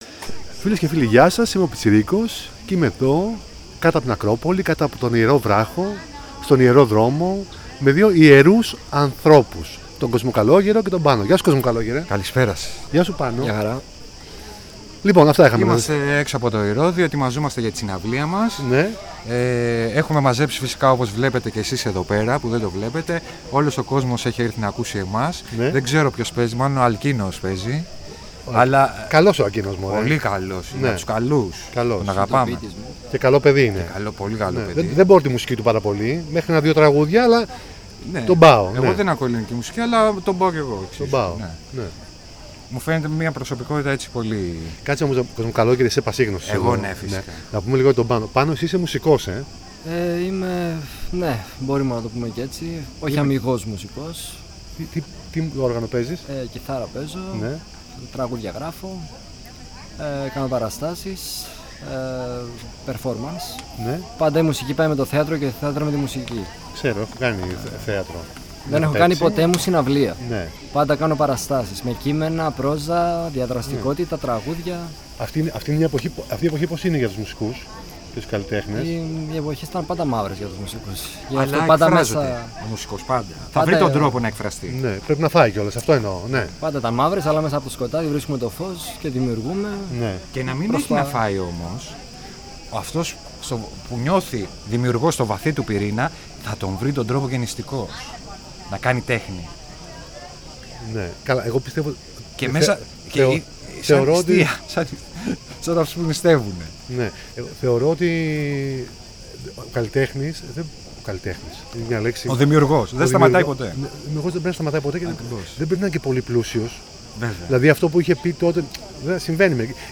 κάτω από την Ακρόπολη